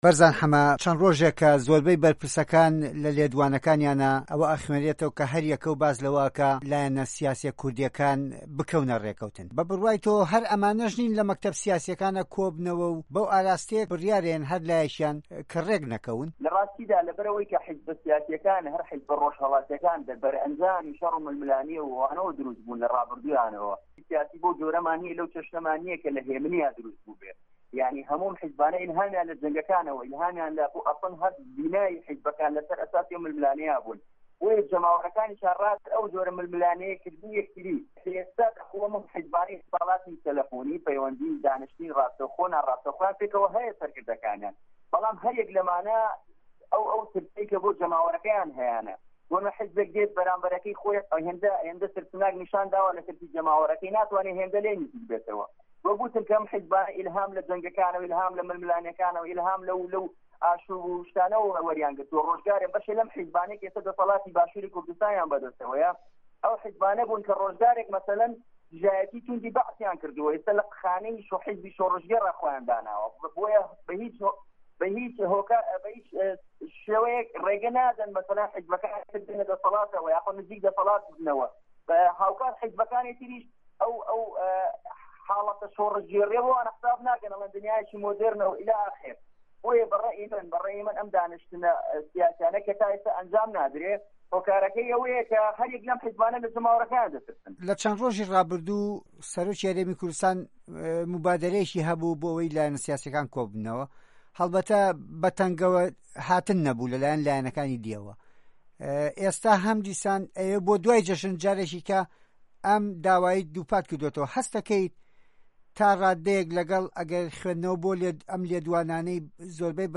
دەقی وتووێژەکە لەم فایلە دەنگیـیەدایە